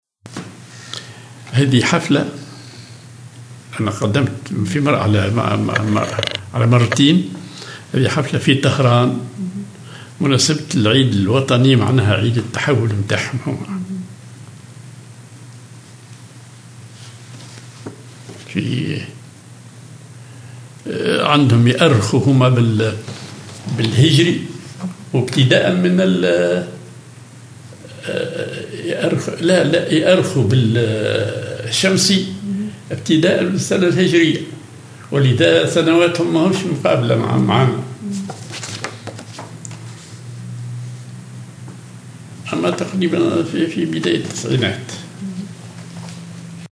حفلة بماسبة العيد الوطني للتحول في طهران
en Ceremony in Tehran on the occasion of Eid shift their